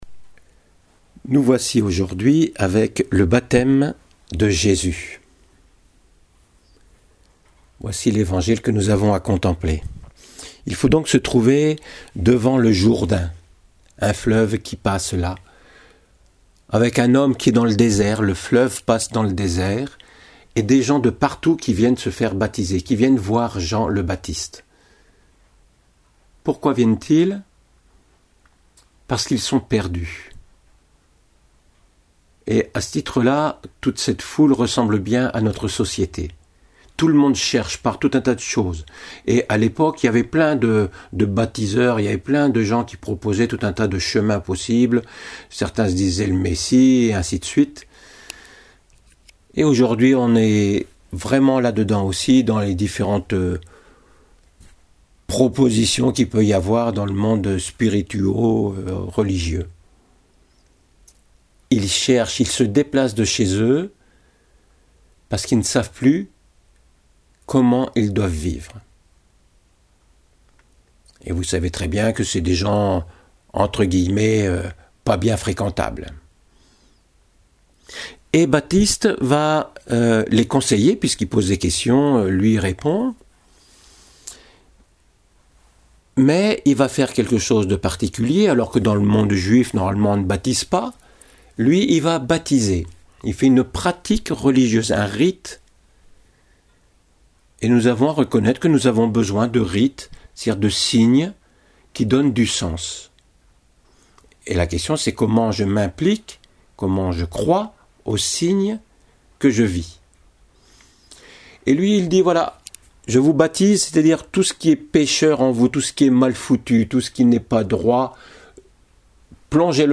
homélie sauver par le baptême du Christ
voici une première homélie en version audio: =>